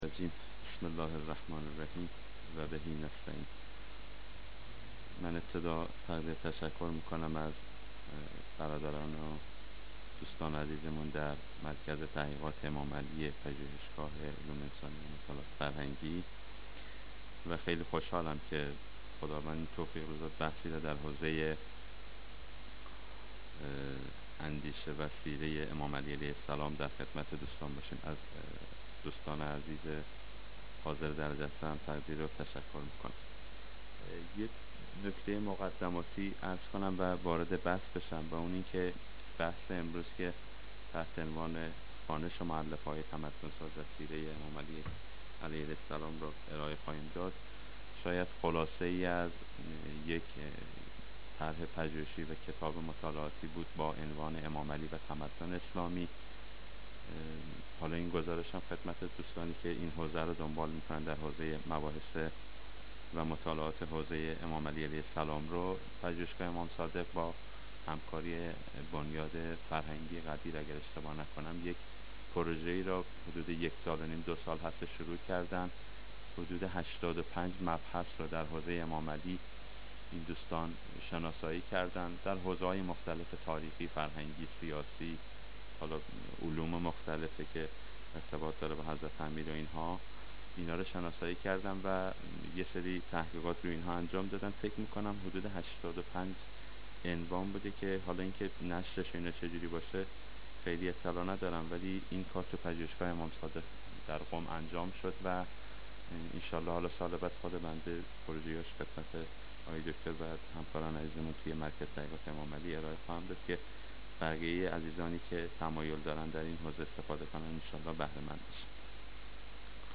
نشست علمی مرکز تحقیقات امام علی علیه السلام